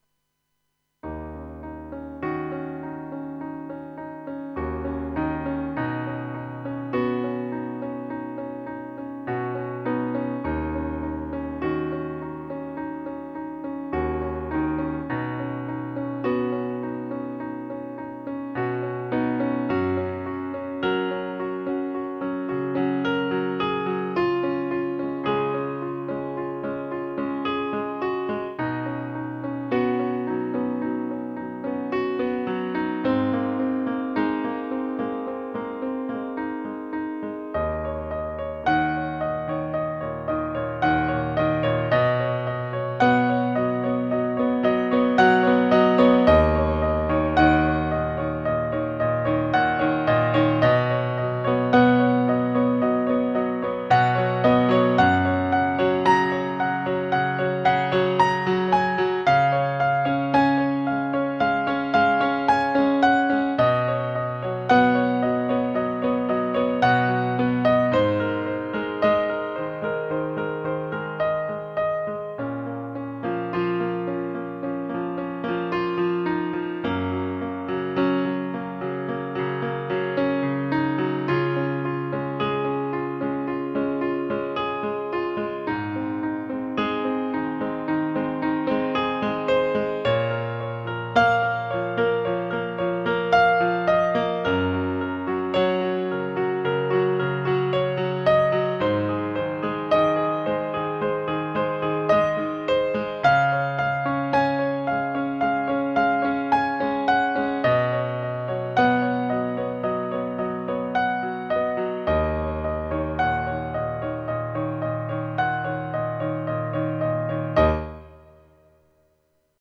Žánr: Indie/Alternativa
Relaxační "easy listening" hudba na piano